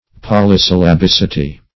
Polysyllabicity \Pol`y*syl`la*bic"i*ty\, n.
polysyllabicity.mp3